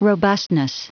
Prononciation du mot robustness en anglais (fichier audio)
Prononciation du mot : robustness